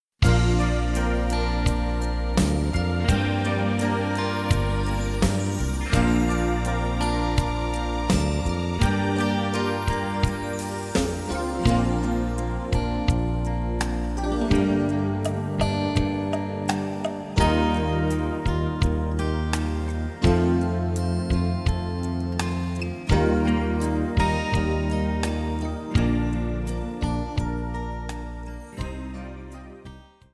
Gattung: für Posaune (inkl. Online-Audio)
Besetzung: Instrumentalnoten für Posaune